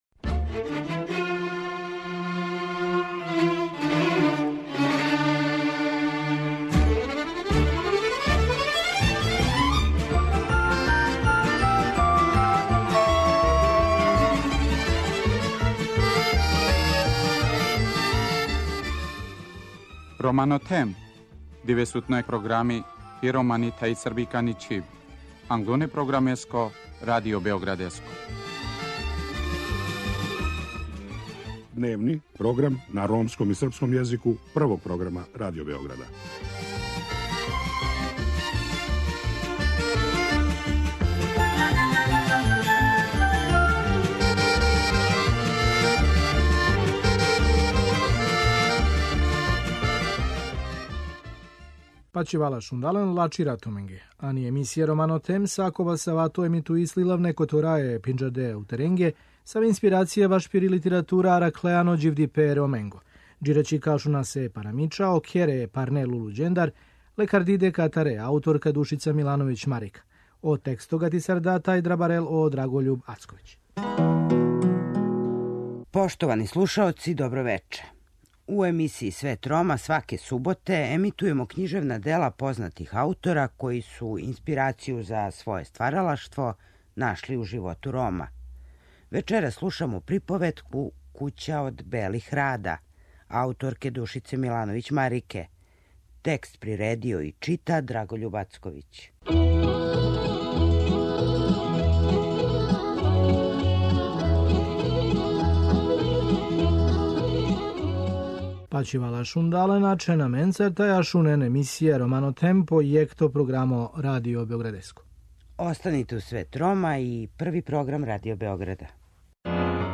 У емисији Свет рома, сваке суботе емитујемо књижевна дела познатих аутора који су инспирацију за своје стваралаштво нашли у животу Рома.